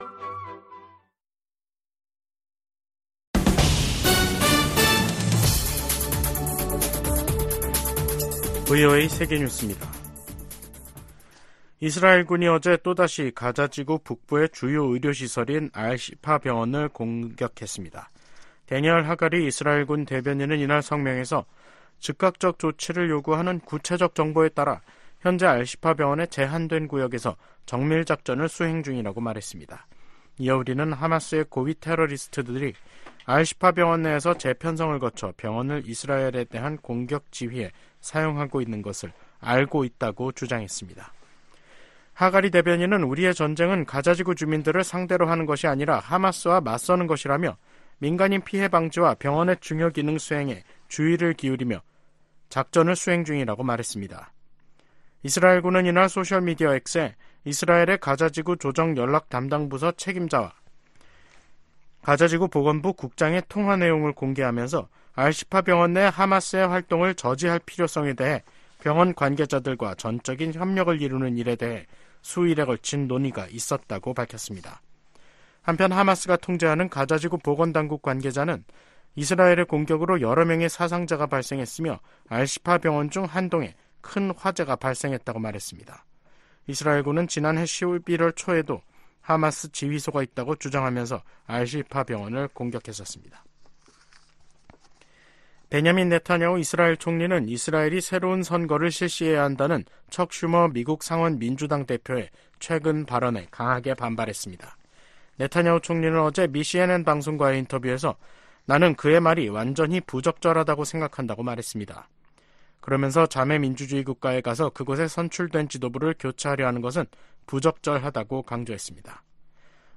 VOA 한국어 간판 뉴스 프로그램 '뉴스 투데이', 2024년 3월 18일 3부 방송입니다.